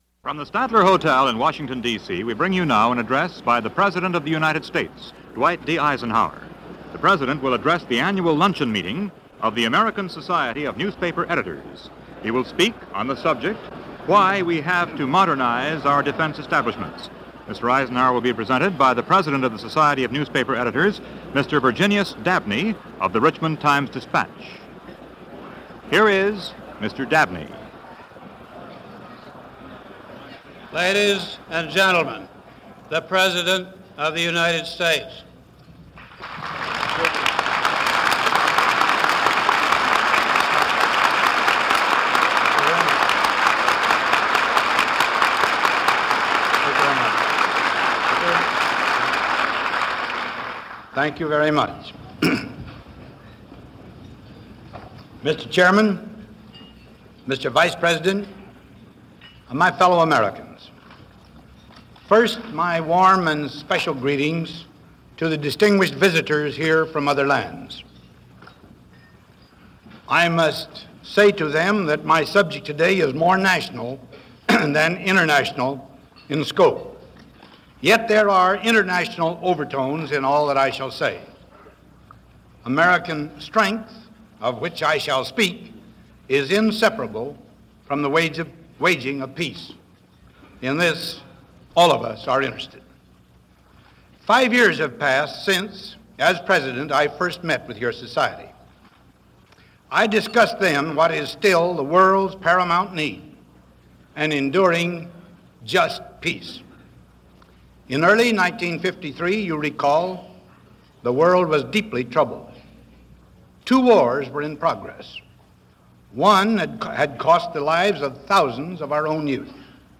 President Eisenhower Addresses The American Society Of Newspaper Editors - April 17, 1958 - Past Daily Reference Room